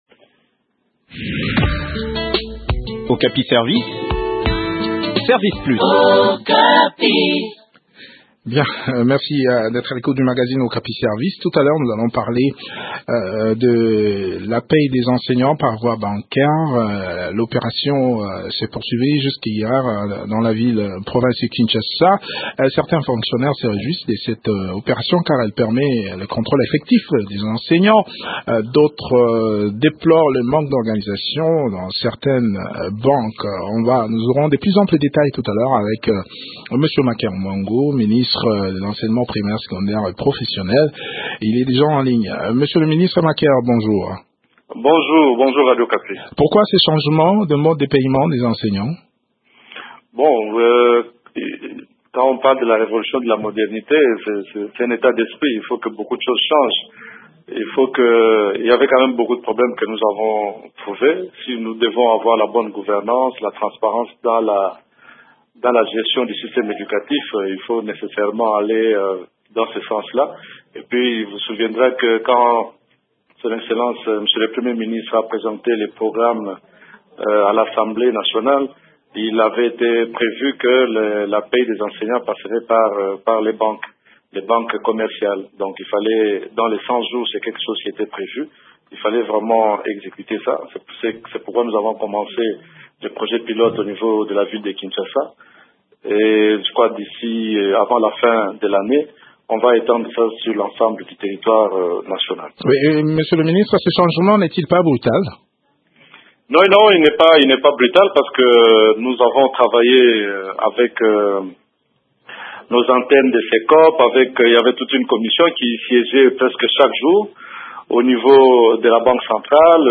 Maker Muangu Famba, ministre de l’enseignement primaire, secondaire et professionnel au studio de la Radio Okapi.